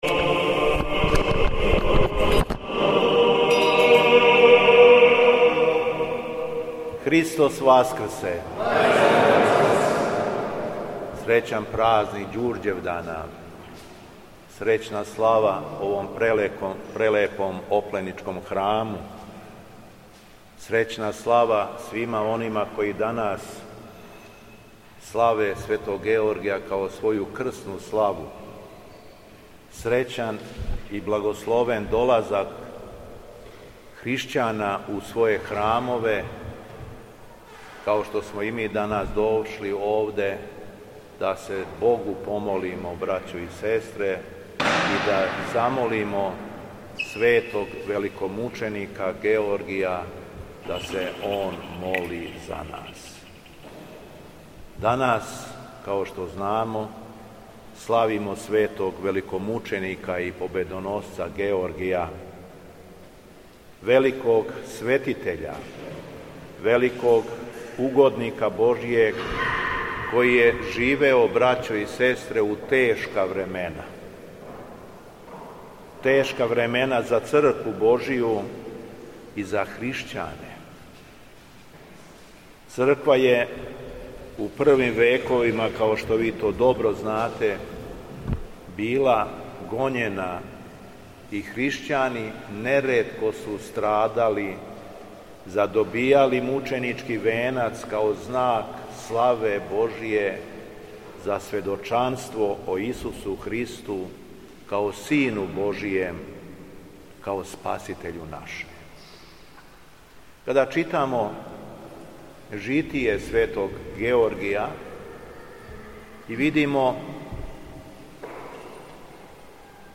Беседа Његовог Високопреосвештенства Митрополита шумадијског г. Јована
После прочитаној Јеванђелског зачала верном народу беседио је владика Јован: